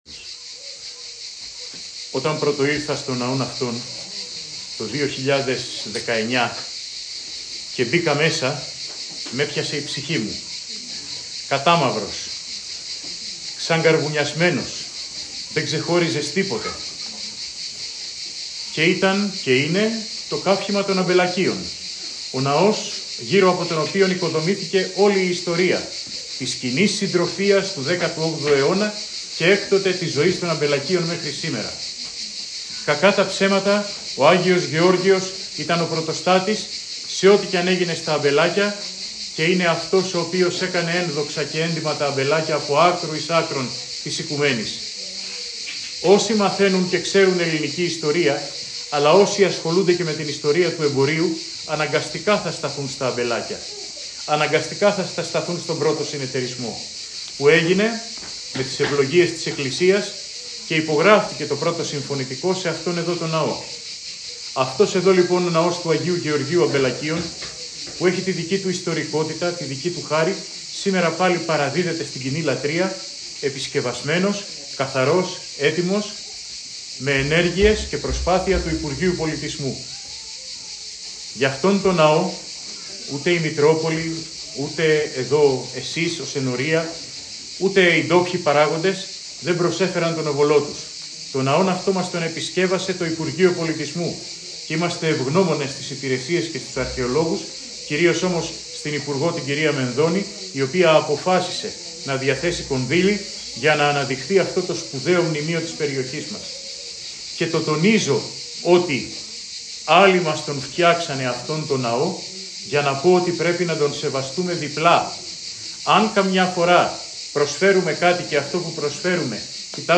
Ακούστε την ομιλία του Μητροπολίτη Λαρίσης και Τυρνάβου κ. Ιερωνύμου: